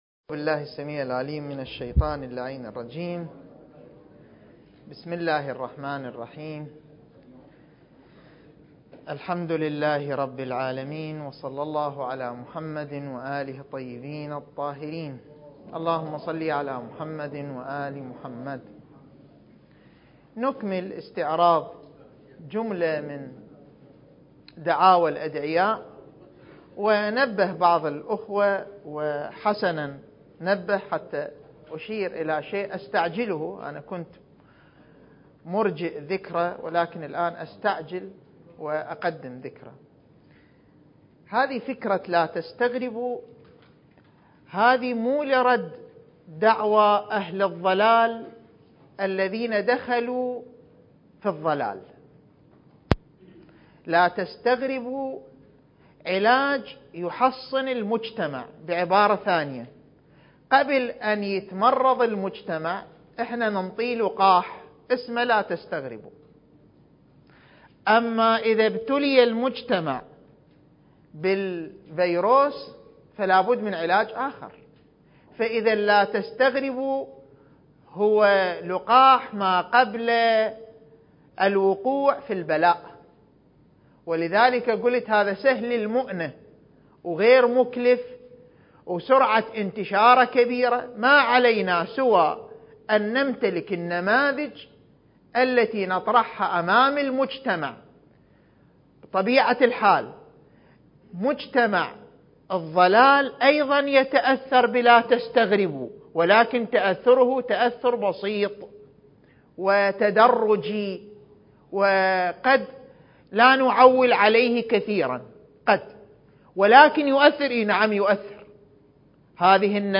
المكان: مؤسسة الإمام الحسن المجتبى (عليه السلام) - النجف الأشرف دورة منهجية في القضايا المهدوية (رد على أدعياء المهدوية) (14) التاريخ: 1443 للهجرة